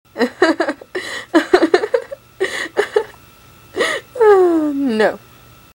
Laughter